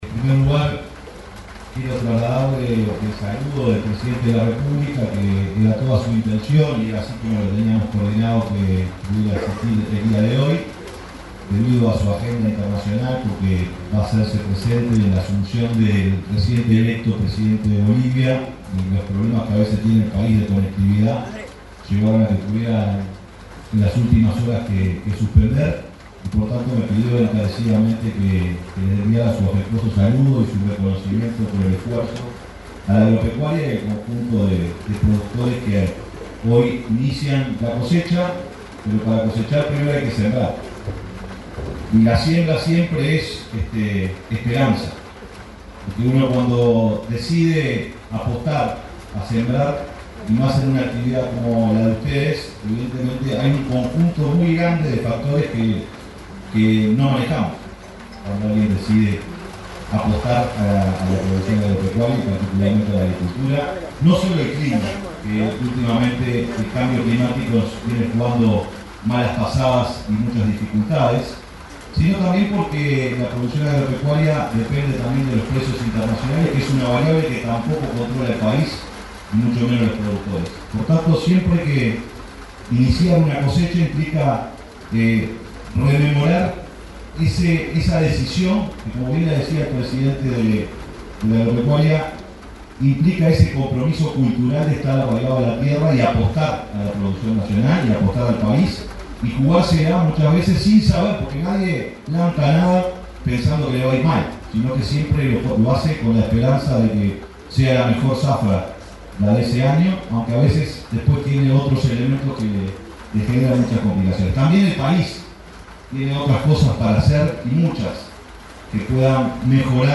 Palabras del secretario de Presidencia, Alejandro Sánchez 07/11/2025 Compartir Facebook X Copiar enlace WhatsApp LinkedIn Al participar de la ceremonia inaugural de la quinta cosecha de arroz, en Dolores, departamento de Soriano, el secretario de Presidencia, Alejandro Sánchez, expresó un discurso alusivo.